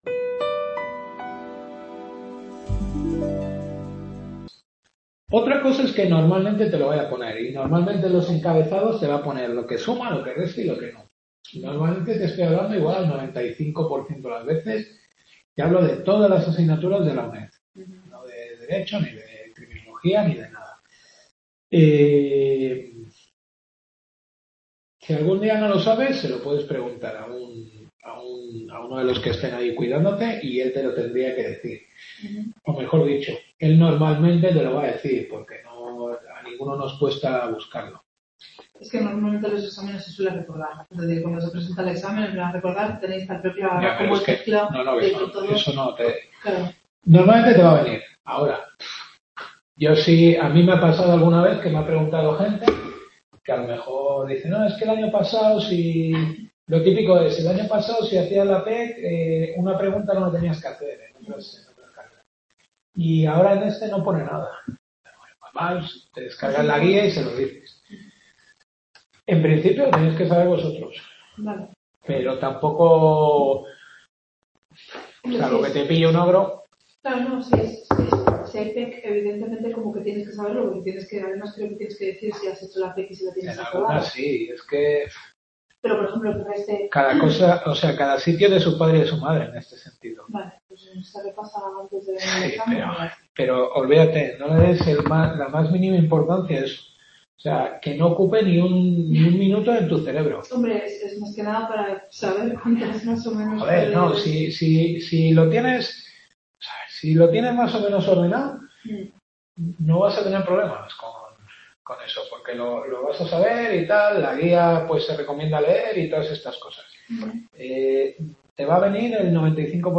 Duodécima Clase.